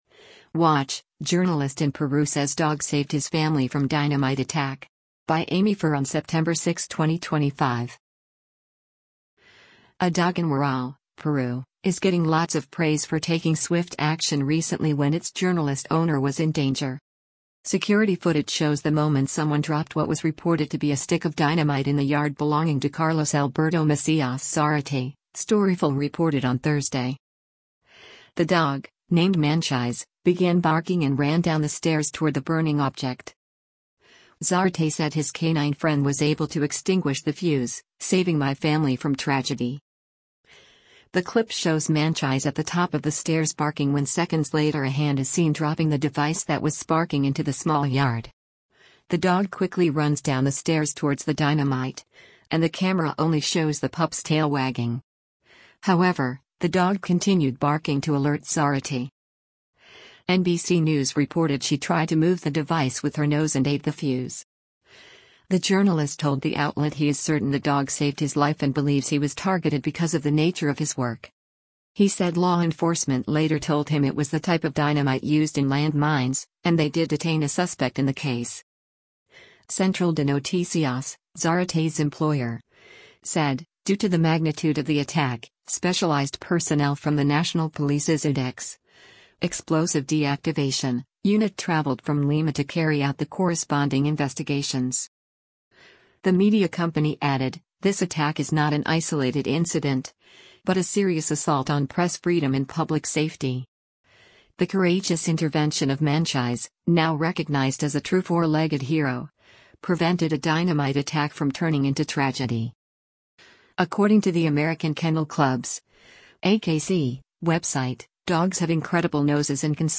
The dog, named Manchis, began barking and ran down the stairs toward the burning object.